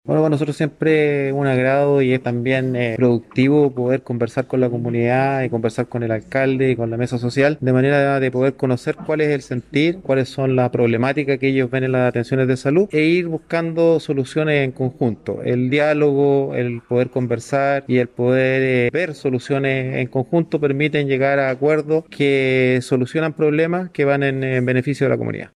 José Cárdenas, Director (S) del Servicio de Salud Chiloé, valoró la posibilidad de conversar con la comunidad  a través de la Mesa Social y autoridades, de manera de poder conocer el sentir de la población y sus problemáticas en materia de atención de salud.